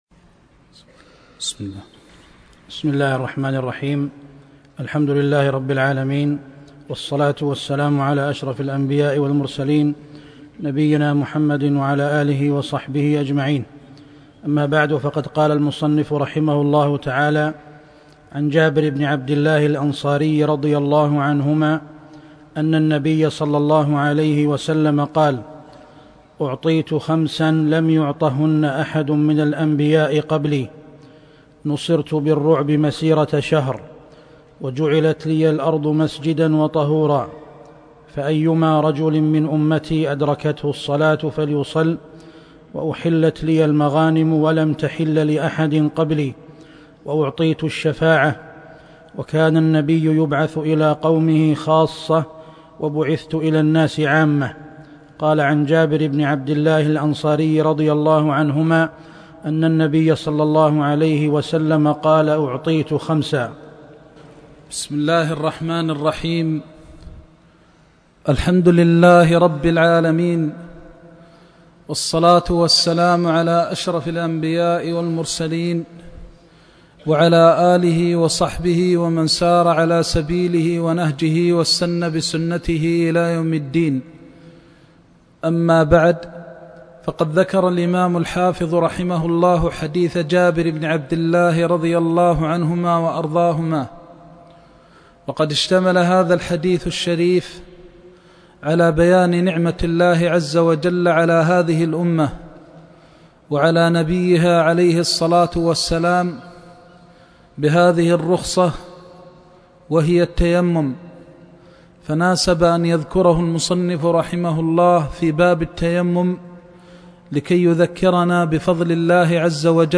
الدرس التاسع والعشر